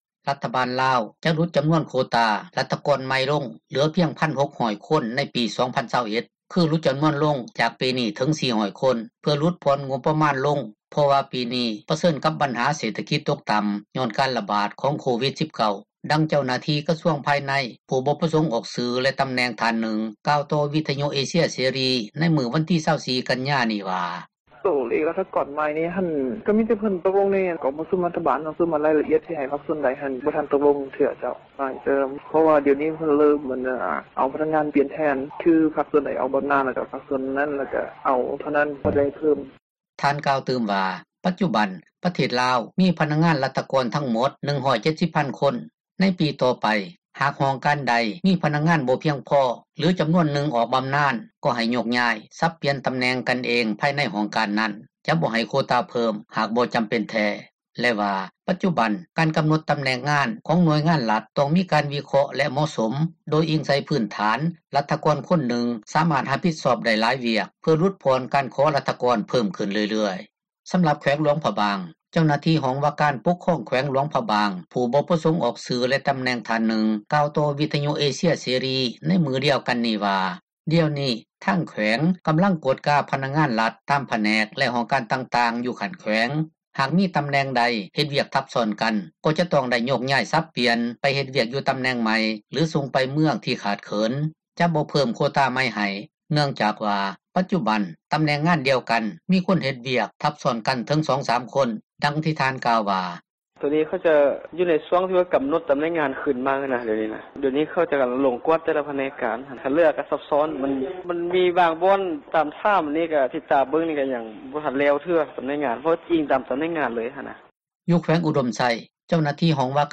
ຣັຖບານລາວ ໄດ້ຮັບຮອງ ຈະຫຼຸດຈໍານວນ ໂຄຕ້າ ຣັຖກອນໃໝ່ ລົງເຫຼືອພຽງ 1,600 ຄົນ ໃນປີ 2021 ຄື ຫຼຸດຈໍານວນລົງ ຈາກປີນີ້ ເຖິງ 400 ຄົນ ເພື່ອຫຼຸດຜ່ອນ ງົບປະມານລົງ ເພາະວ່າປີນີ້ ປະເຊີນກັບ ບັນຫາເສຖກິຈ ຕົກຕໍ່າ ຍ້ອນການຣະບາດ ຂອງໂຄວິດ-19. ດັ່ງເຈົ້າໜ້າທີ່ ກະຊວງພາຍໃນ ຜູ້ບໍ່ປະສົງອອກຊື່ ແລະ ຕໍາແໜ່ງ ທ່ານນຶ່ງ ກ່າວຕໍ່ ວິທຍຸເອເຊັຽເສຣີ ໃນມື້ວັນທີ 24 ກັນຍາ ນີ້ວ່າ: